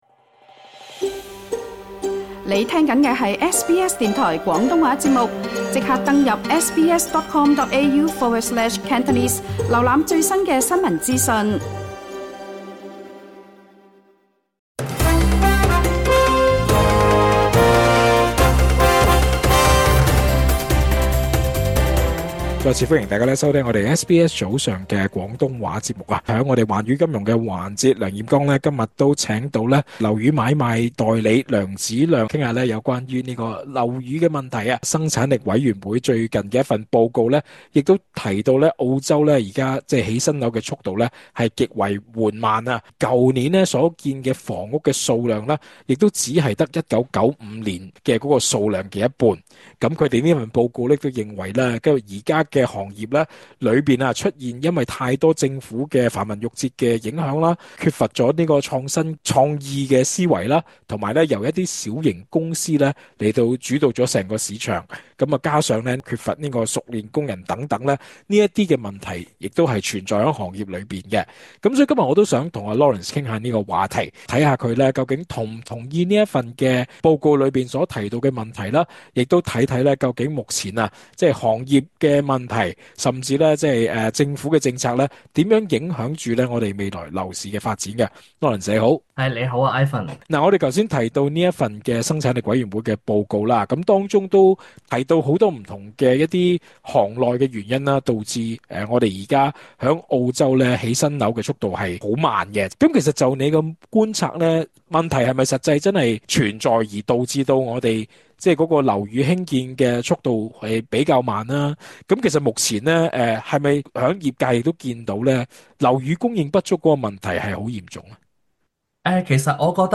更多詳情請留意足本訪問。